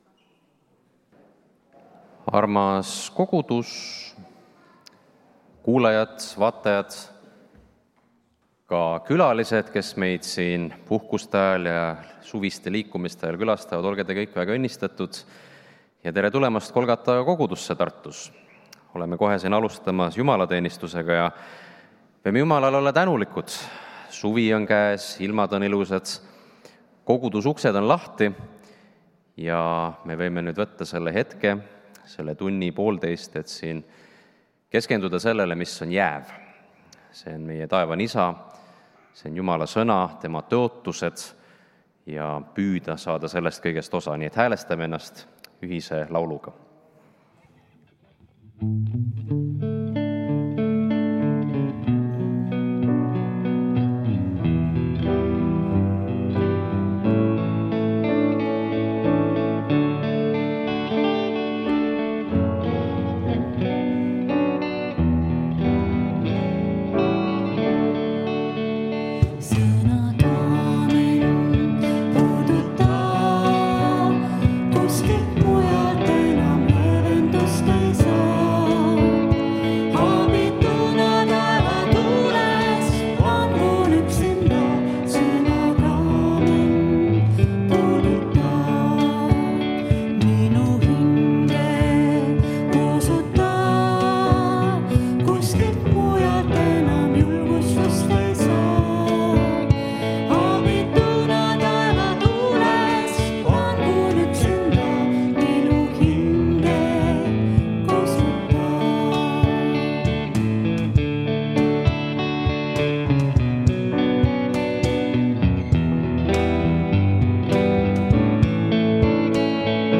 Pühakirja lugemine: Lk 12:13-34